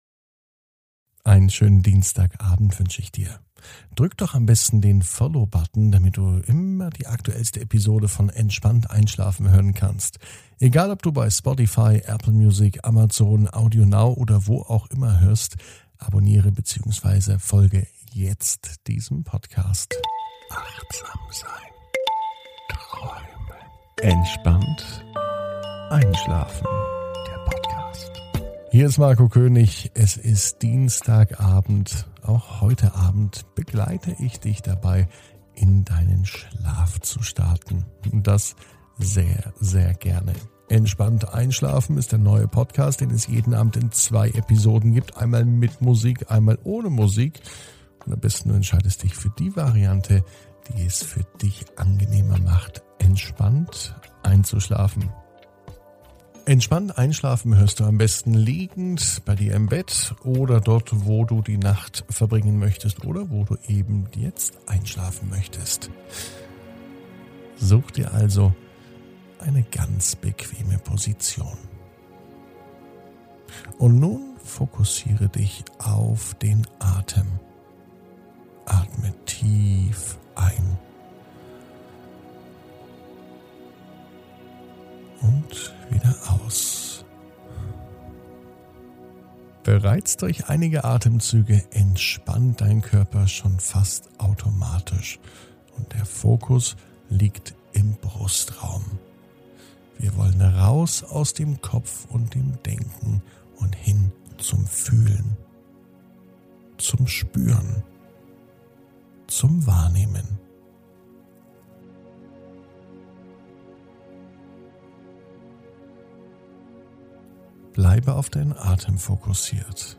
Heute startet der neue Einschlafpodcast Entspannt einschlafen.